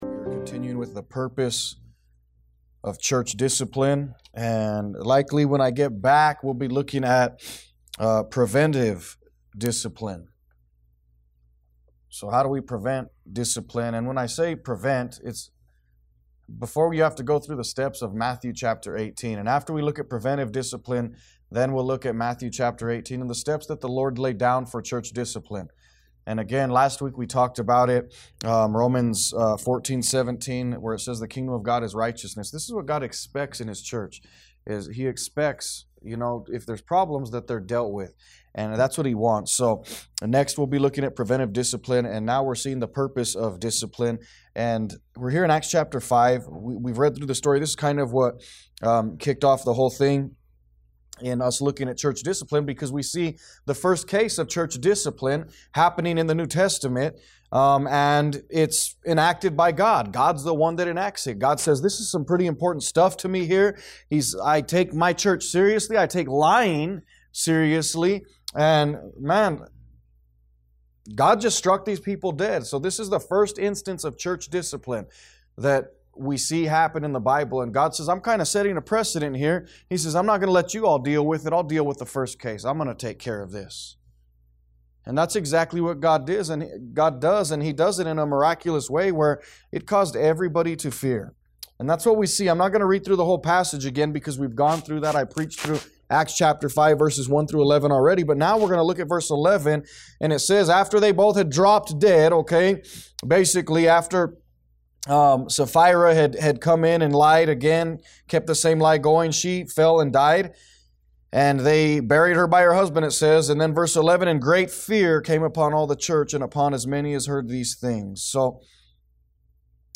A message from the series "Church Discipline."